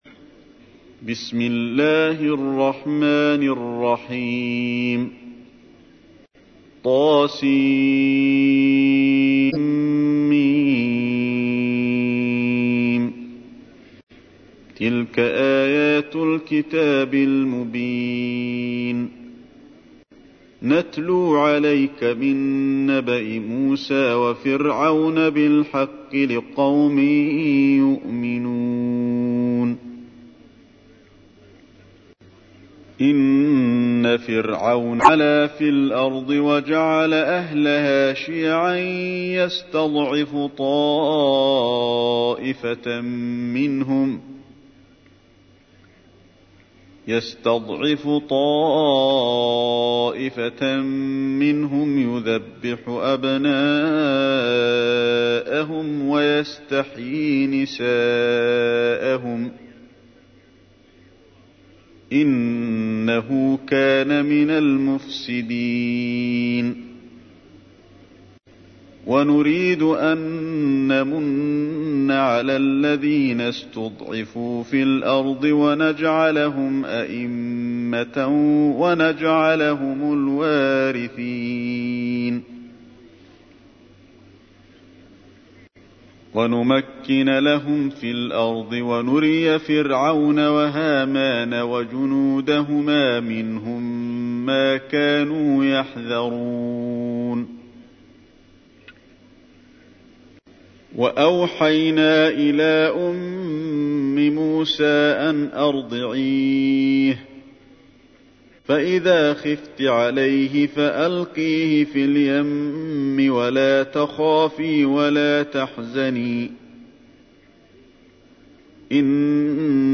تحميل : 28. سورة القصص / القارئ علي الحذيفي / القرآن الكريم / موقع يا حسين